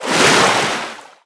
Sfx_creature_penguin_dive_shallow_03.ogg